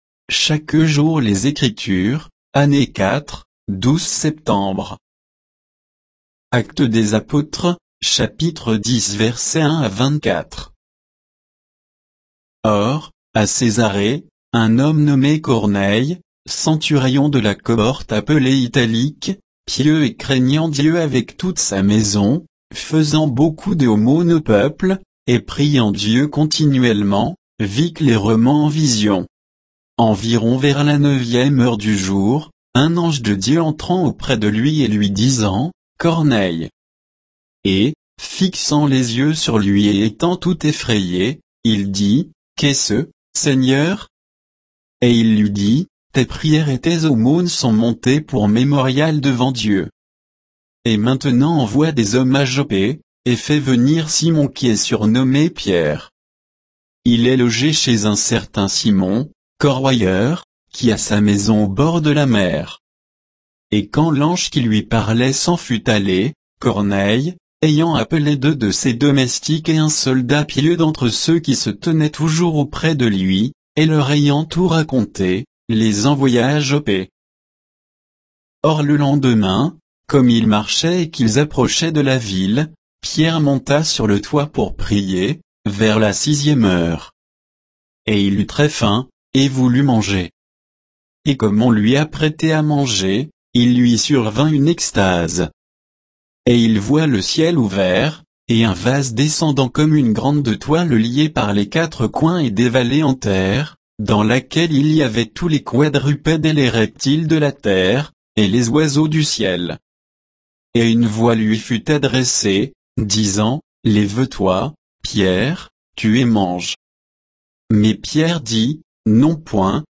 Méditation quoditienne de Chaque jour les Écritures sur Actes 10